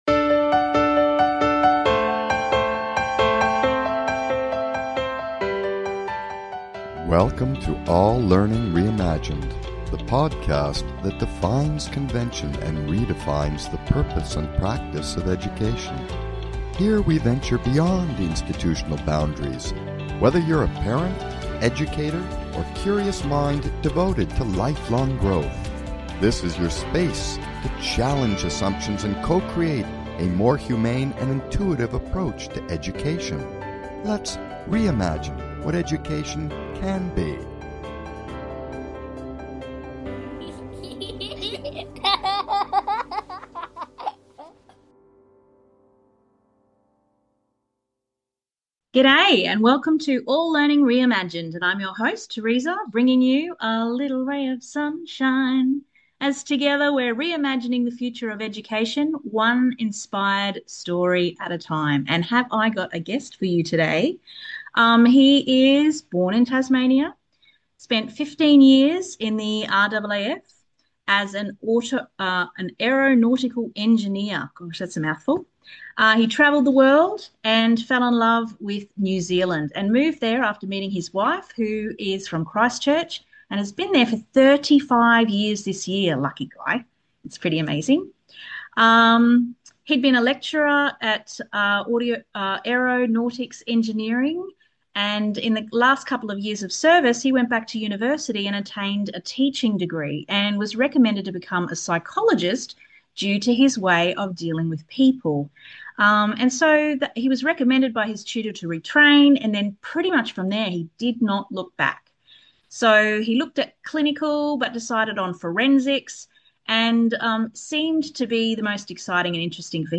Talk Show Episode, Audio Podcast, All Learning Reimagined and Freedom to Explore Learning on , show guests , about Freedom to Explore Learning, categorized as Education,Entertainment,Kids & Family,Music,Philosophy,Psychology,Self Help,Society and Culture,Variety